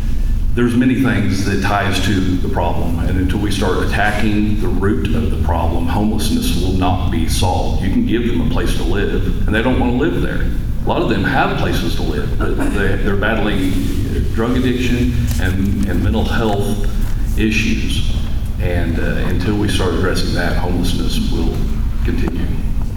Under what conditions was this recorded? The Osage County Republican Party held a GOP Forum on Tuesday evening at the Osage County Fairgrounds.